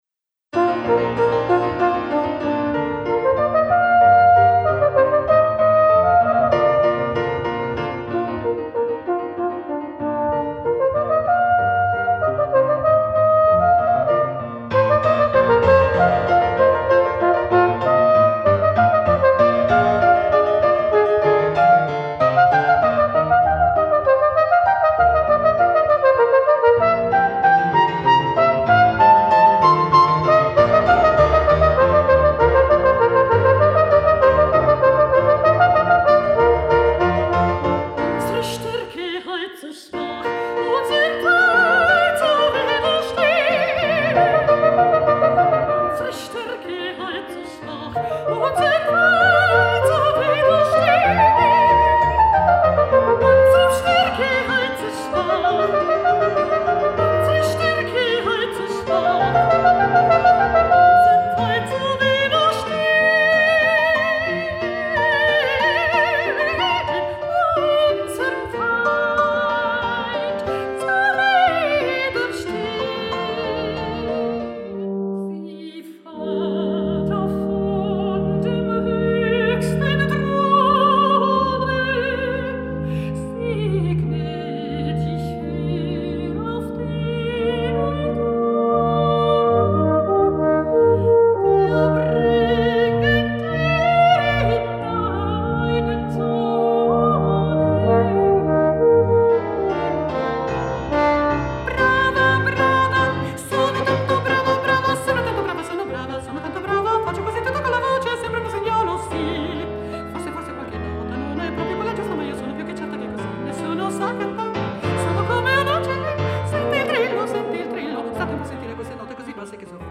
Studio di registrazione e sale prova a Capriolo, Brescia.